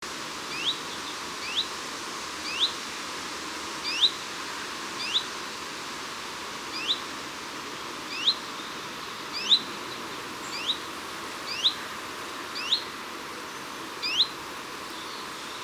Sekalaulava uunilintu / A song switching Phylloscopus warbler
Äänite 2  Kutsuääni, tiltalttimainen Recording 2  Call, Chiffchaff-like